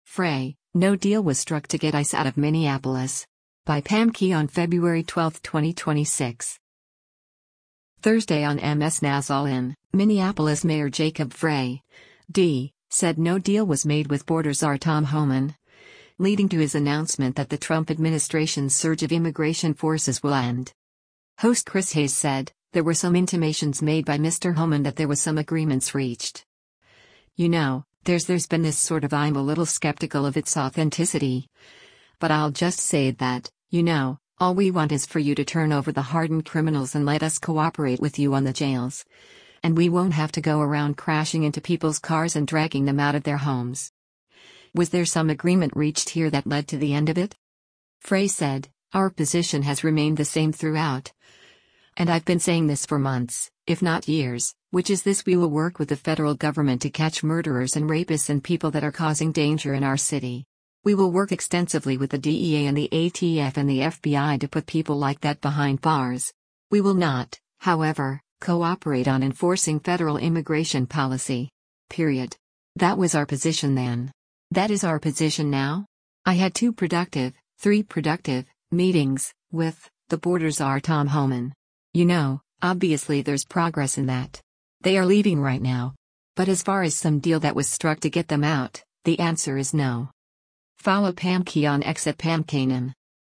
Thursday on MS NOW’s “All In,” Minneapolis Mayor Jacob Frey (D) said no deal was made with border czar Tom Homan, leading to his announcement that the Trump administration’s surge of immigration forces will end.